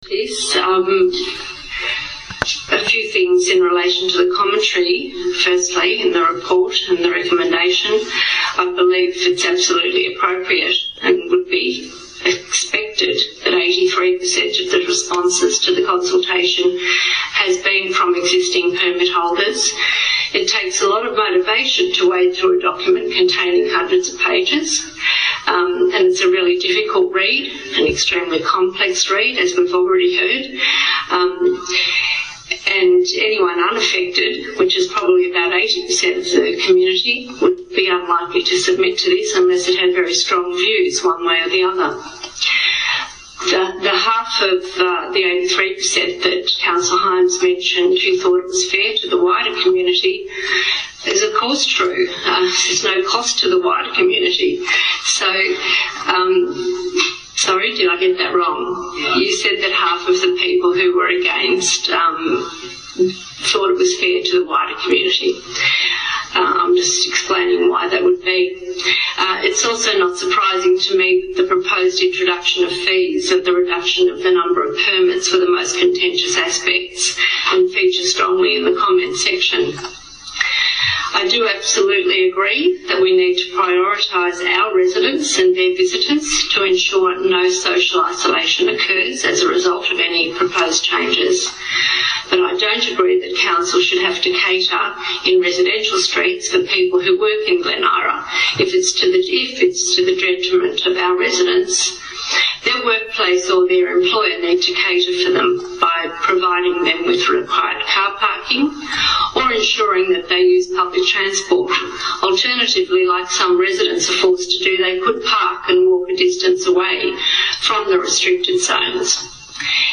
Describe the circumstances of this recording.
Last night’s council meeting showed the first public sign that maybe things aren’t as hunky dory within this councillor group as they would like us to believe.